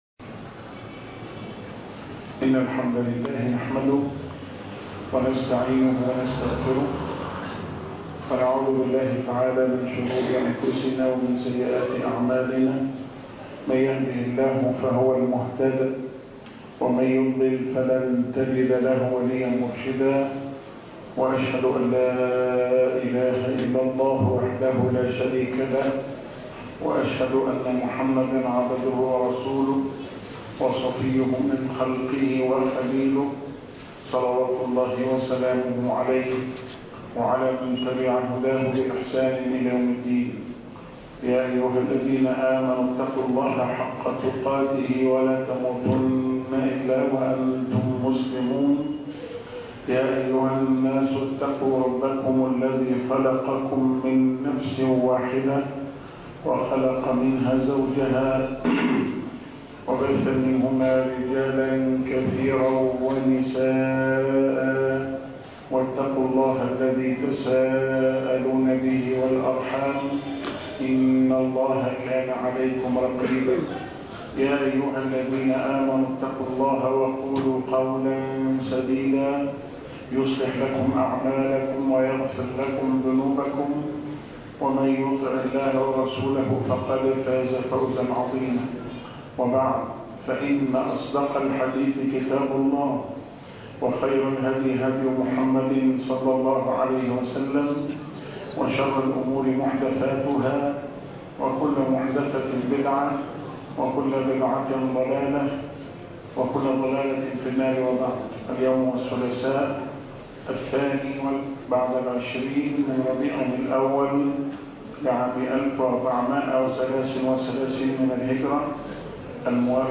الدرس ( 45)اخلاقه بكائه ضحكه - شرح كتاب زاد المعاد